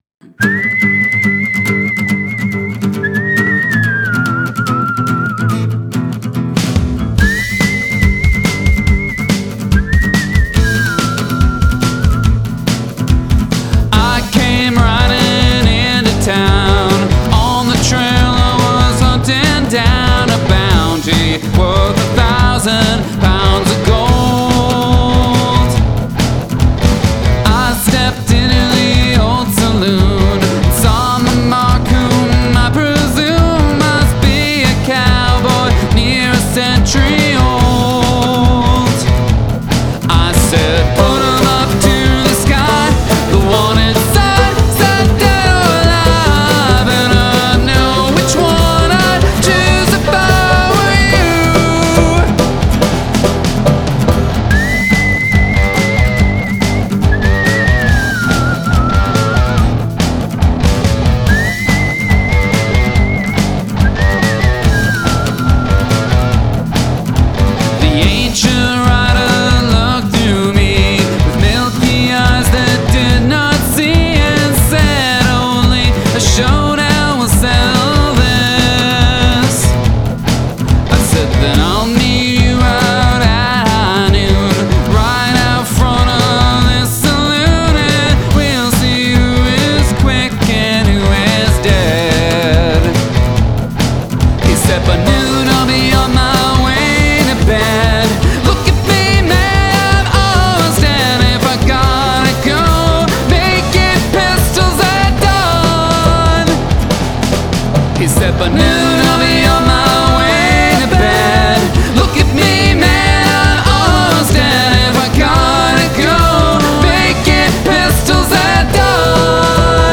I like the western vibes